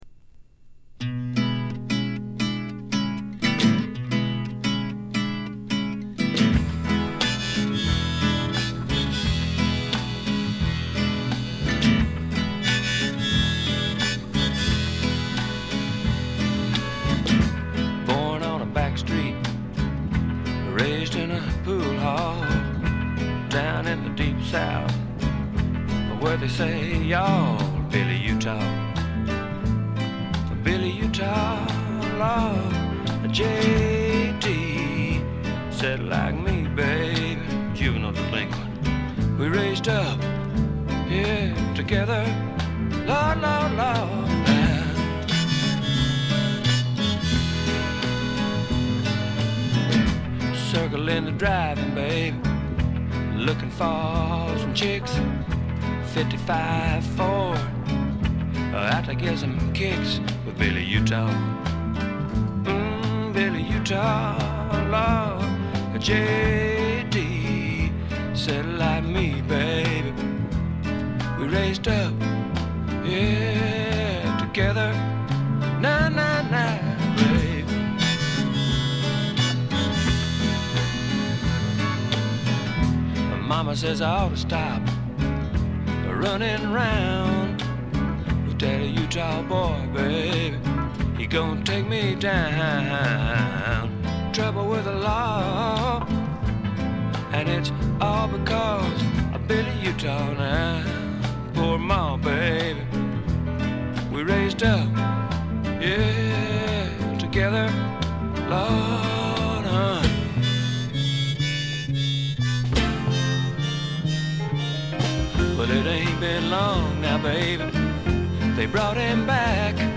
部分試聴ですが、ごくわずかなノイズ感のみ。
あまりナッシュビルぽくないというかカントリーぽさがないのが特徴でしょうか。
試聴曲は現品からの取り込み音源です。
Guitar, Vocals, Piano, Vibes